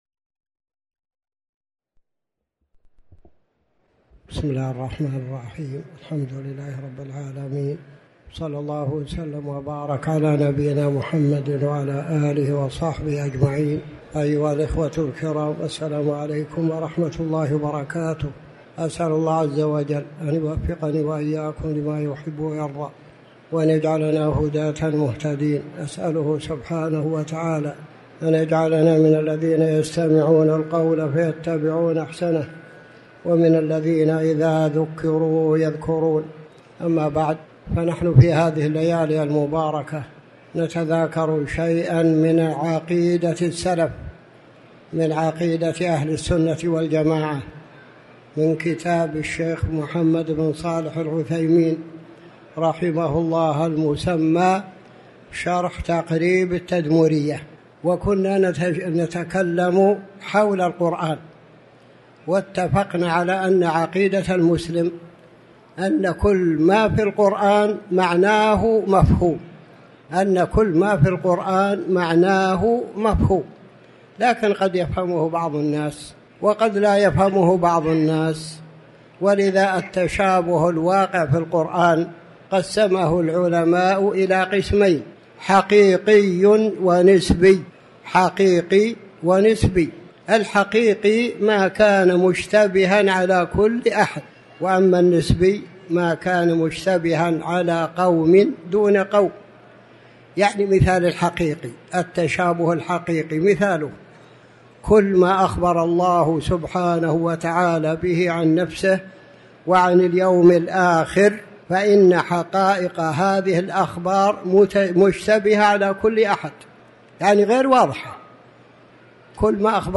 تاريخ النشر ١١ ربيع الثاني ١٤٤٠ هـ المكان: المسجد الحرام الشيخ